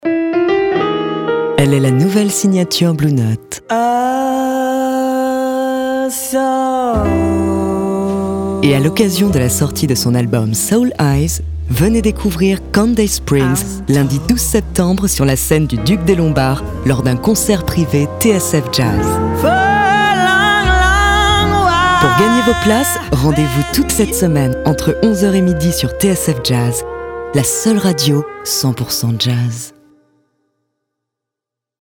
Voix off
Voix radio TSF JAZZ